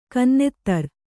♪ kannettar